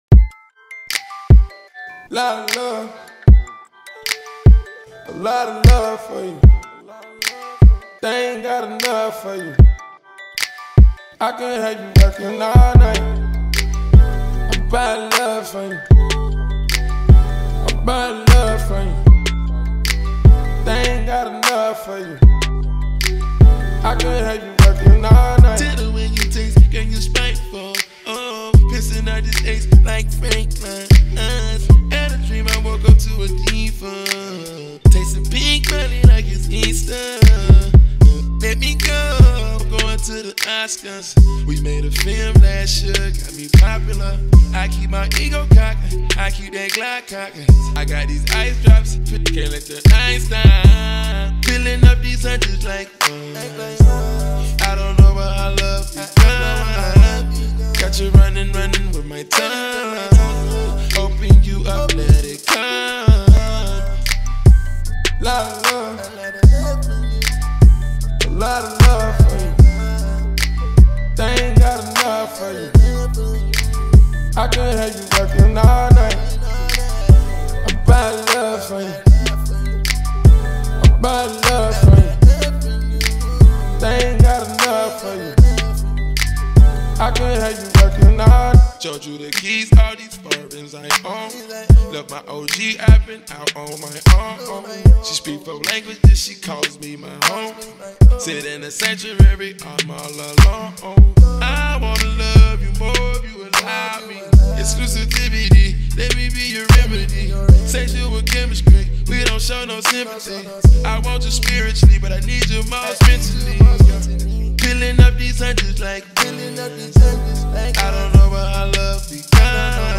HipHop/Rnb